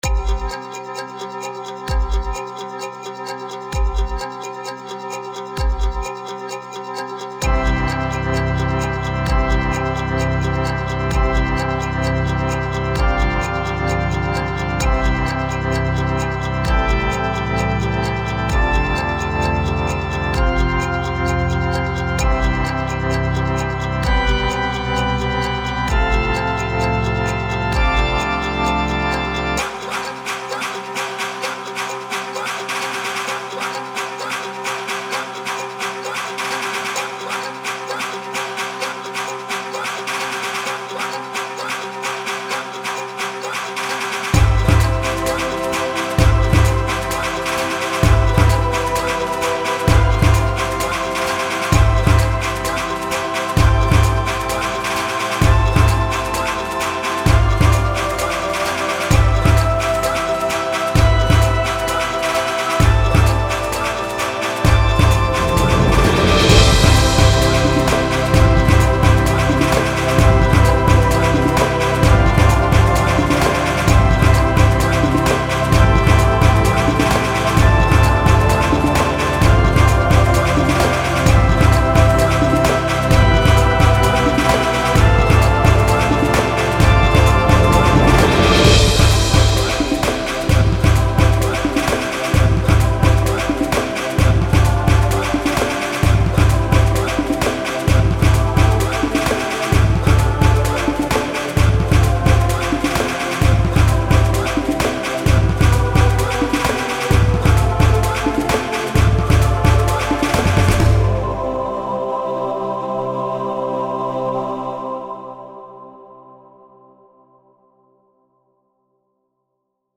tribal
No idea what's happening in this tribal track.